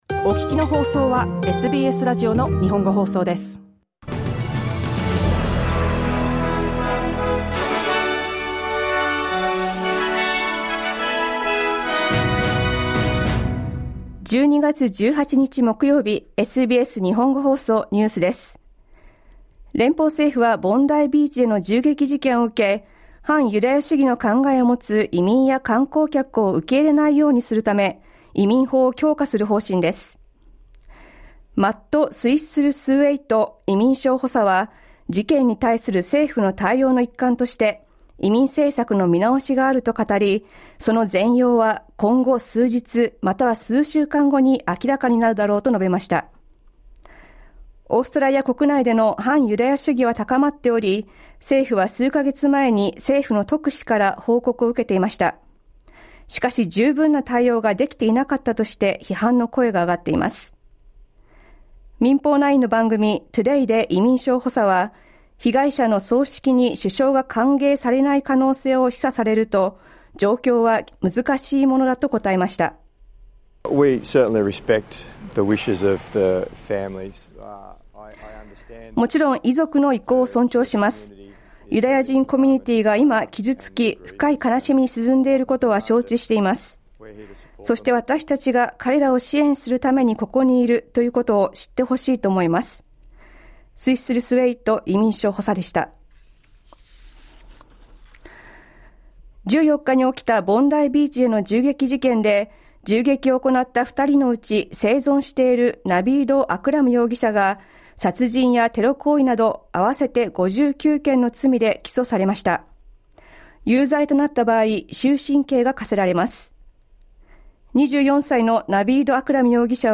The Coalition has responded to the Labor government's Mid-Year Economic and Fiscal Outlook, criticising the Albanese government for continuing what they call reckless spending. News from today's live program (1-2pm).